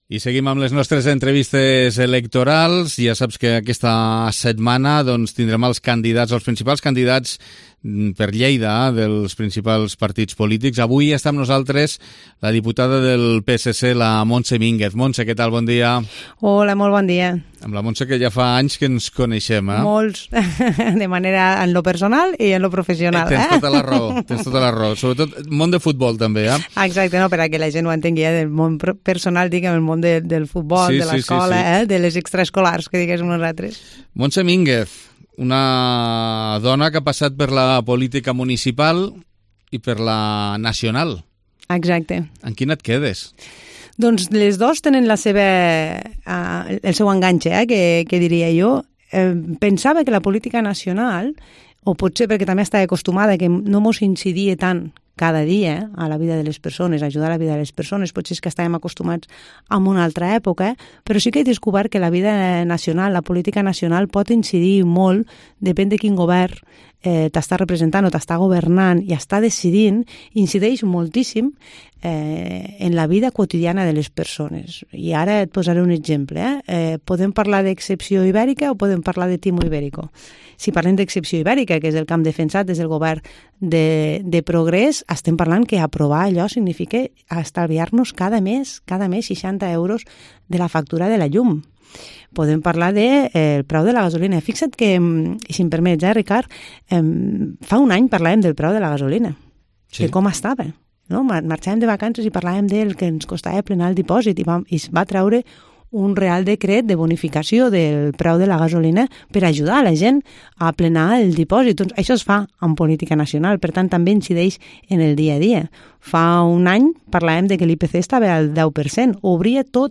Entrevista Campanya Electoral 23J2023 - Montse Minguez - PSC